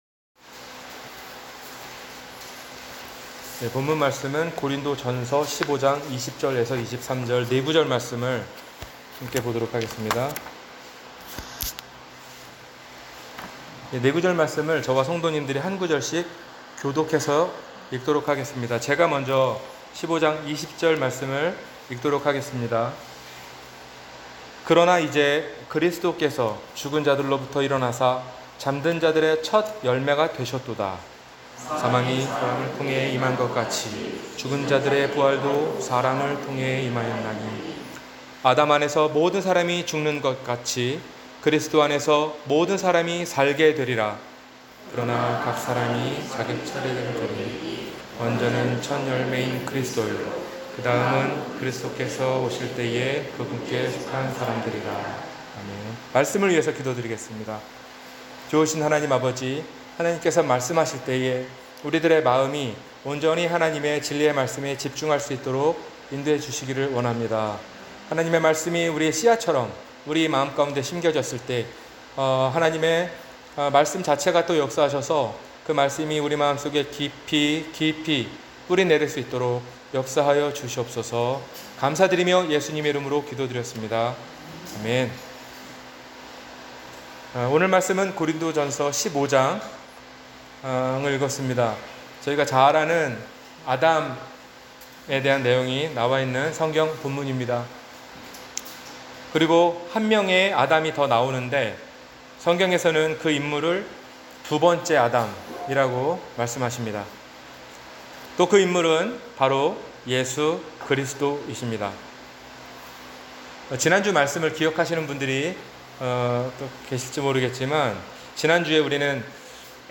두번째 아담 – 주일설교